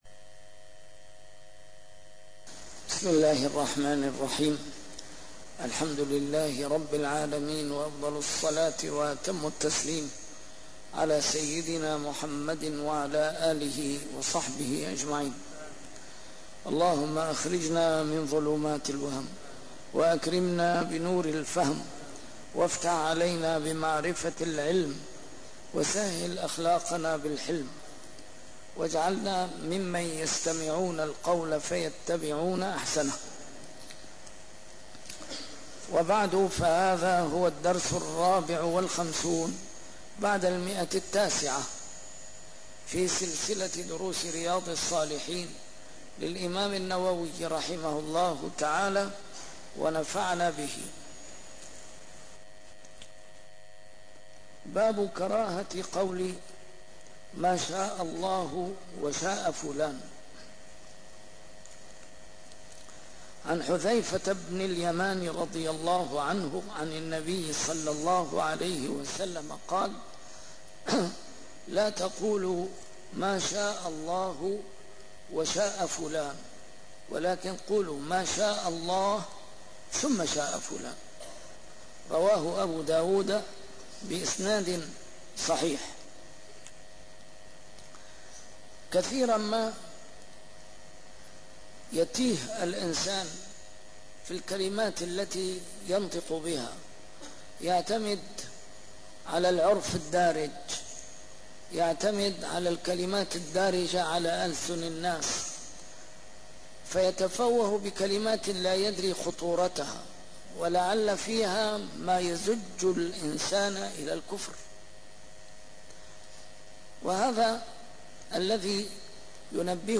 A MARTYR SCHOLAR: IMAM MUHAMMAD SAEED RAMADAN AL-BOUTI - الدروس العلمية - شرح كتاب رياض الصالحين - 954- شرح رياض الصالحين: كراهة قول: ما شاء الله وشاء فلان - كراهة الحديث بعد العشاء الآخرة